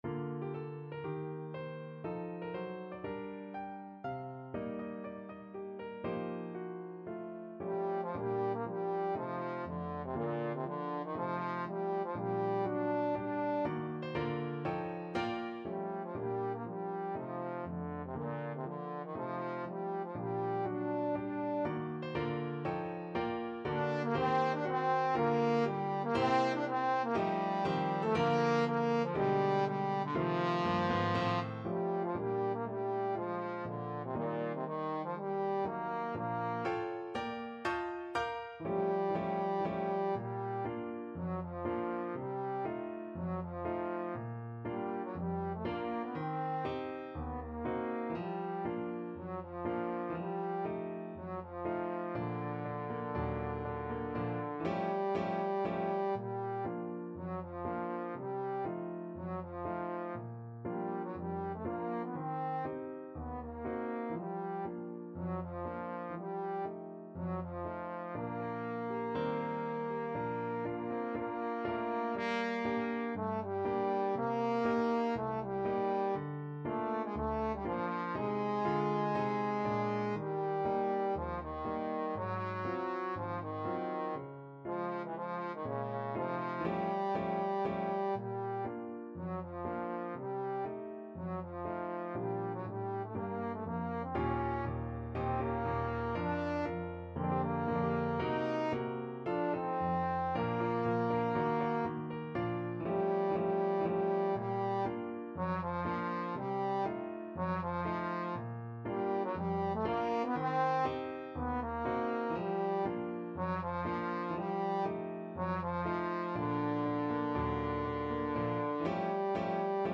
~ = 120 Moderato
4/4 (View more 4/4 Music)